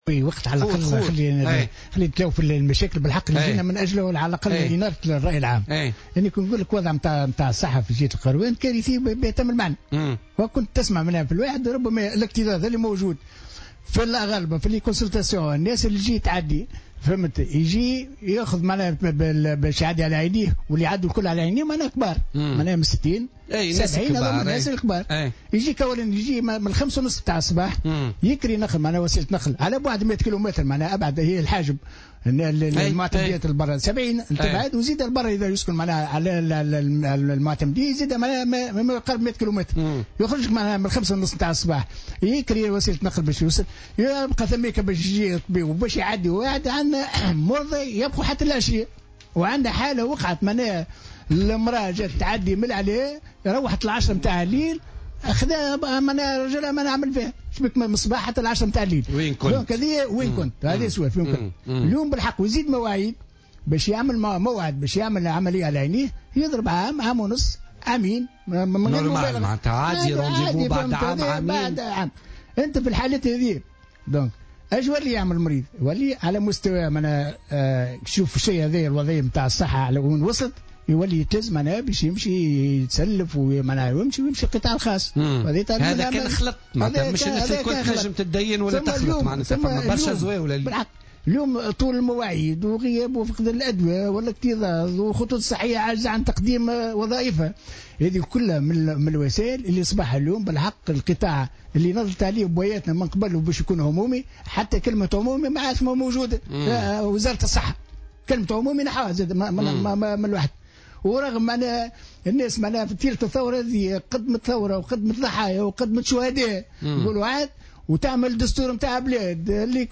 وأوضح في مداخلة له اليوم في برنامج "بوليتيكا" أن الاكتظاظ وطول المواعيد ونقص الأدوية وغيرها من الإشكاليات الأخرى من شأنه أن يجبر المرضى ذوي الدخل الضعيف الى التوجه إلى القطاع الخاص.